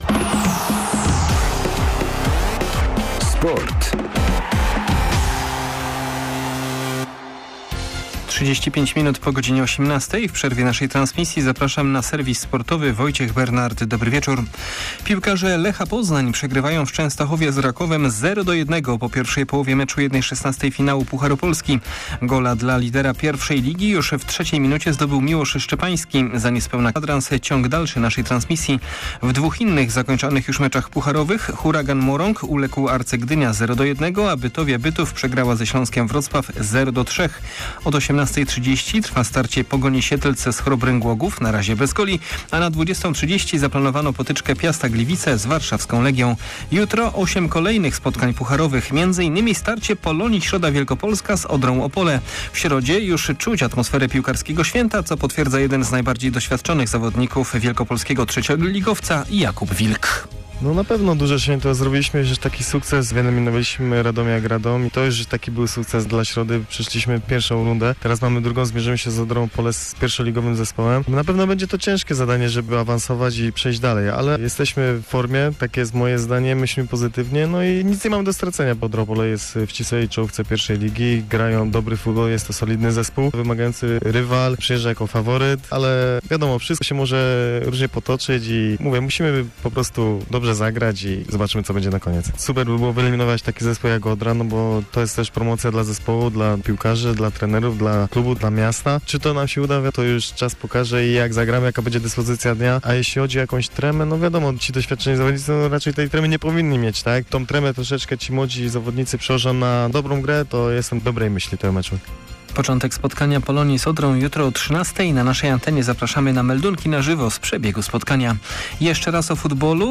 30.10. SERWIS SPORTOWY GODZ. 19:05
Tym razem nasz serwis w przerwie transmisji z pucharowego meczu Lecha Poznań. Zaprosimy między innymi na środowe spotkanie drugiej drużyny z naszego regionu - Polonii Środa Wlkp.